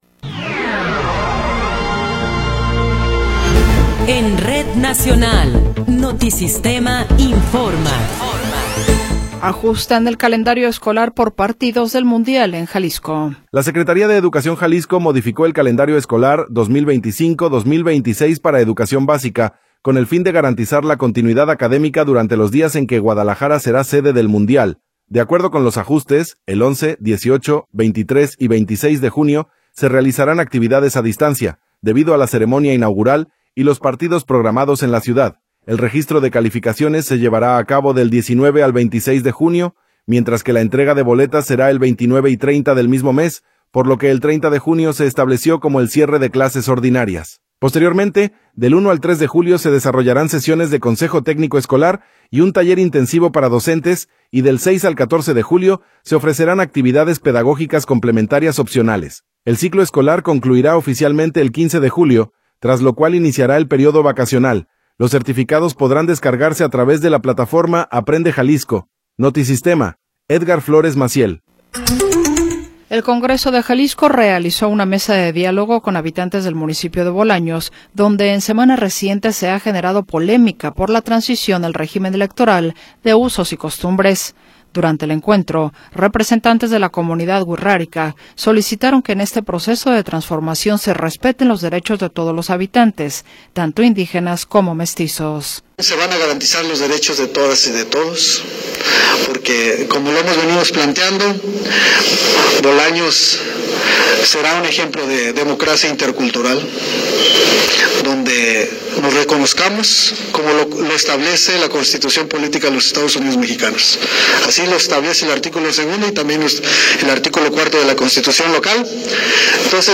Noticiero 16 hrs. – 15 de Abril de 2026